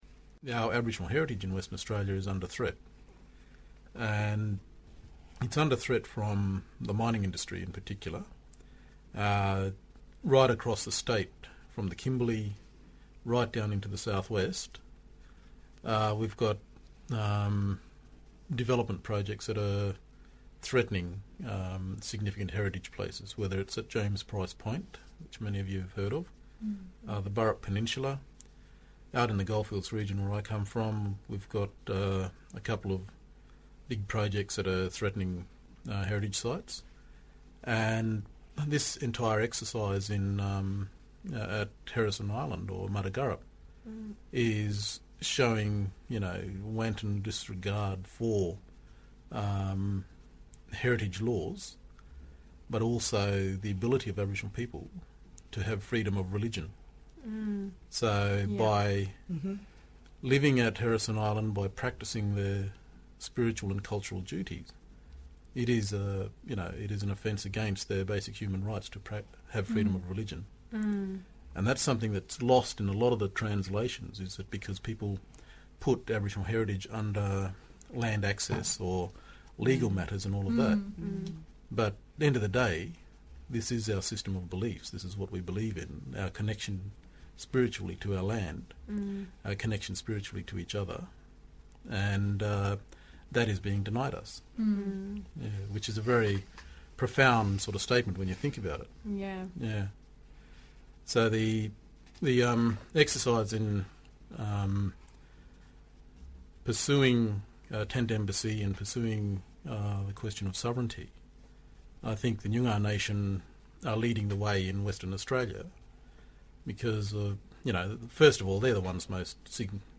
The full interview